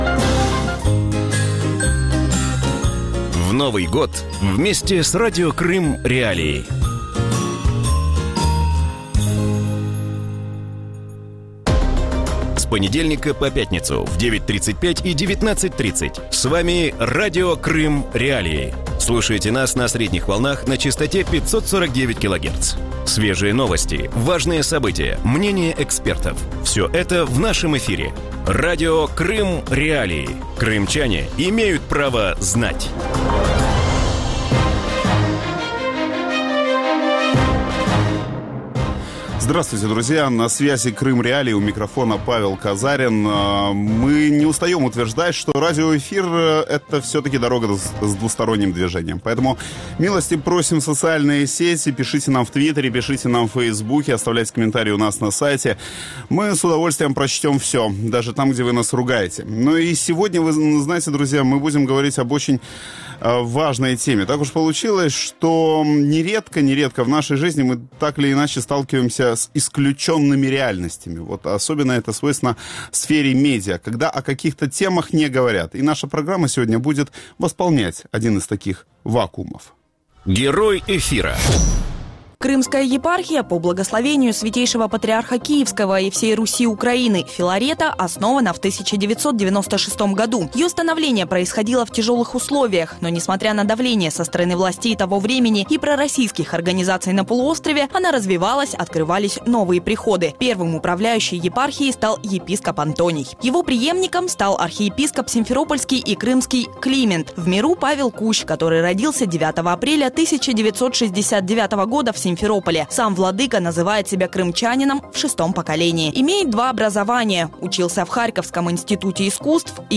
Радио Крым.Реалии/ Интервью с Архиепископом Симферопольским и Крымским Климентом